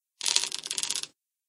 step1.ogg